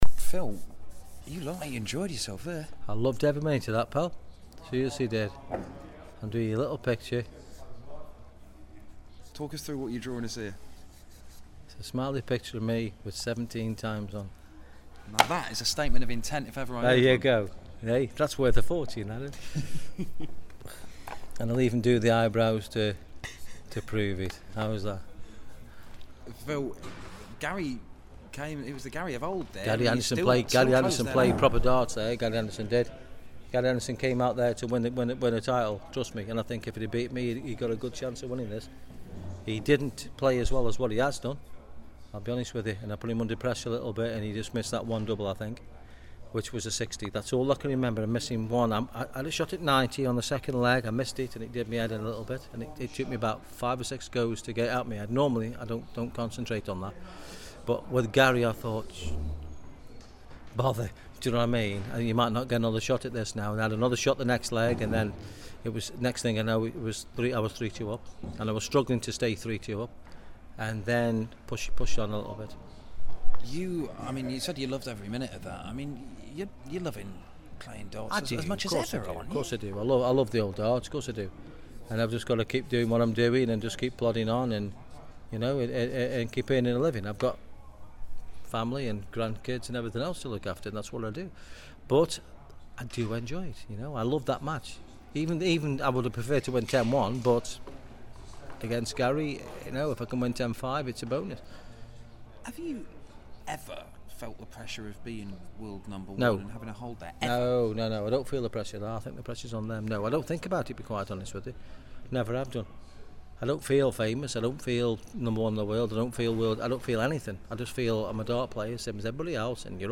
Grand Slam of Darts - Taylor interview